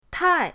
tai4.mp3